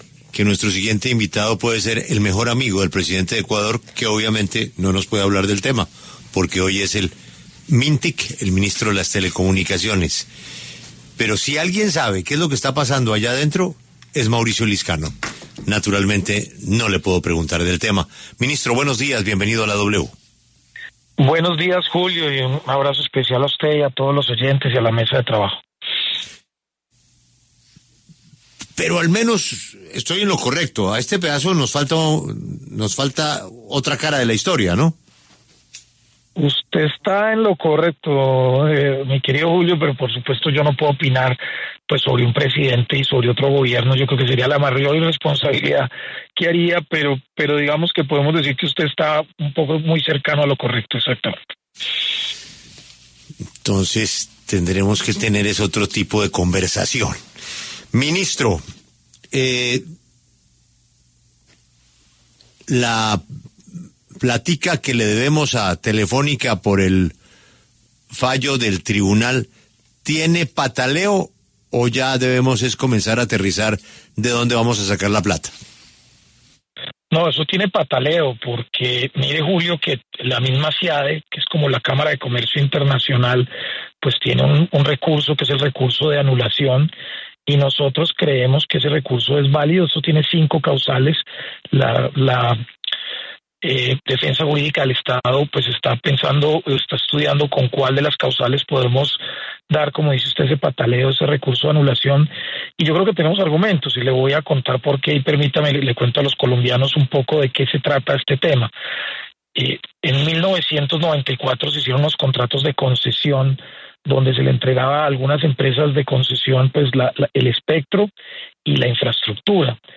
En entrevista con W Radio, el ministro de las TIC, Mauricio Lizcano, explicó en qué consiste este litigio internacional que falló a favor de Telefónica.